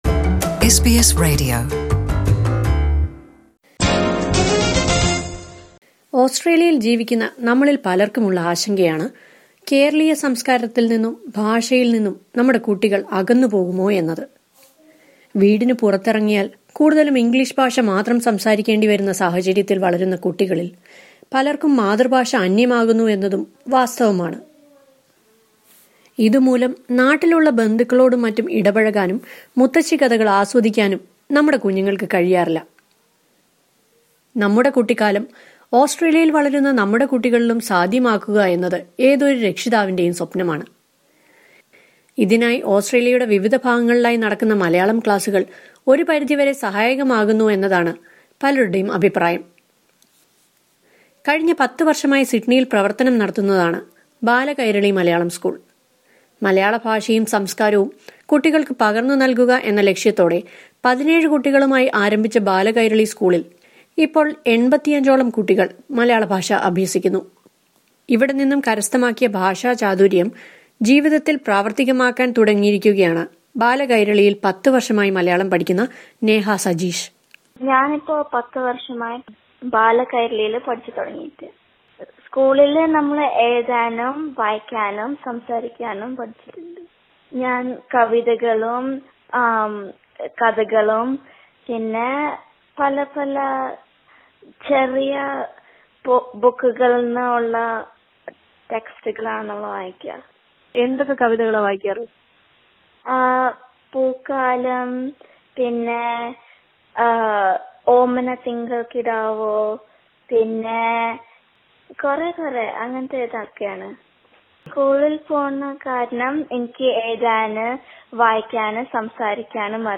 ഓസ്‌ട്രേലിയയുടെ വിവിധ ഭാഗങ്ങളിൽ കുട്ടികൾക്കായി സജ്ജീവമായി പ്രവർത്തിക്കുന്ന മലയാള ഭാഷാ പഠന ക്ലാസുകൾ നടക്കുന്നു. ഇതേക്കുറിച്ച് ഒരു റിപ്പോർട്ട് കേൾക്കാം മുകളിലെ പ്ലേയറിൽ നിന്ന്...